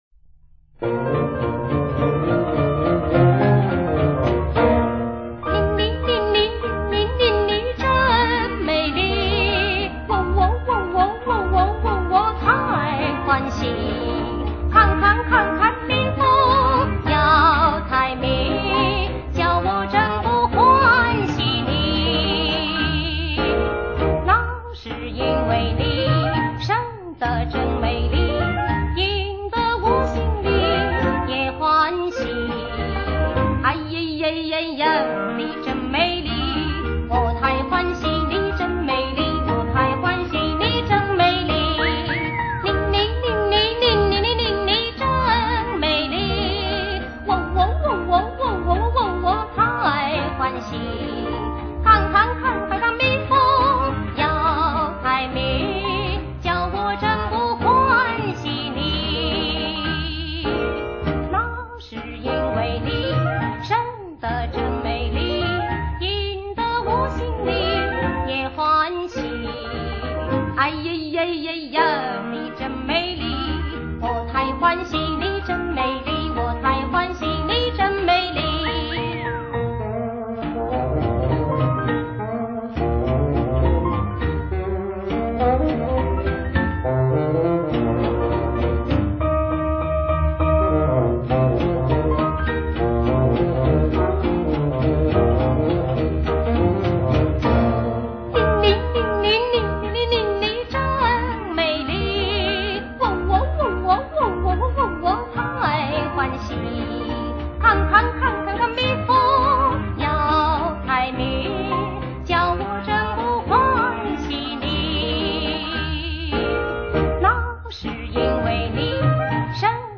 如常强调，此系列的录音，在质方面，应考虑到歌曲都是几十年前的东